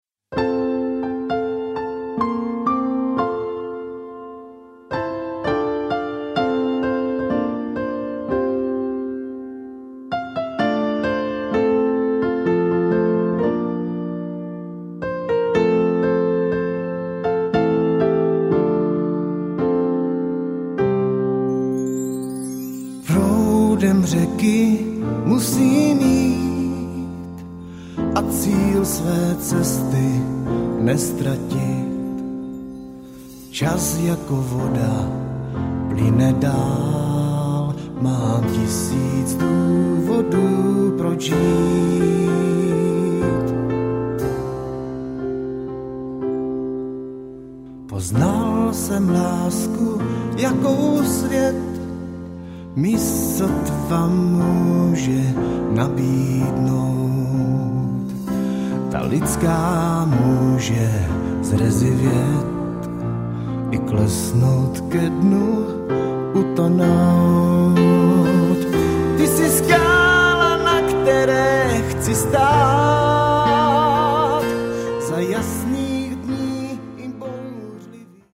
Žánr: Pop.